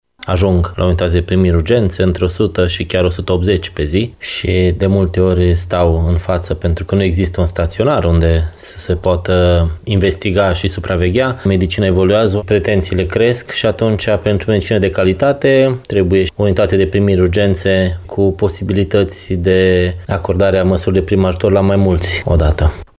Vestea noului UPU îi bucură și pe cei de la serviciul de ambulanță. Medic de urgențe